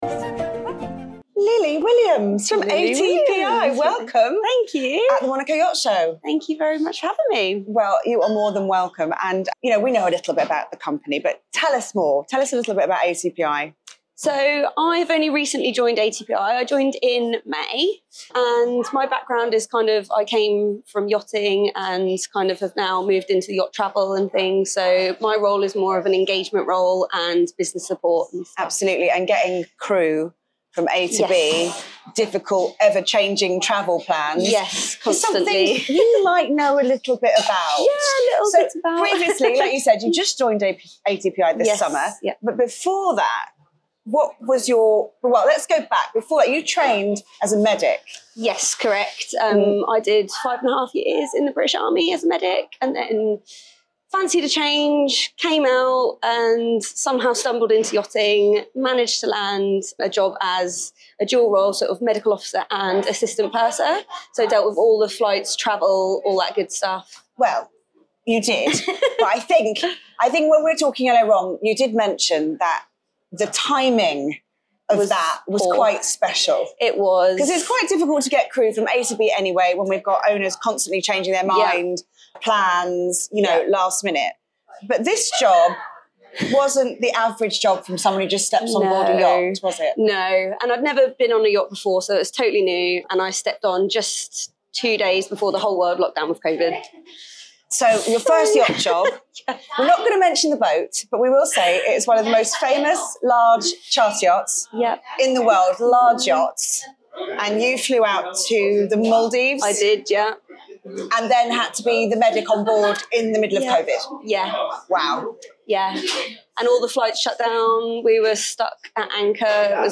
Yachting Channel
interview
at the Monaco Yacht Show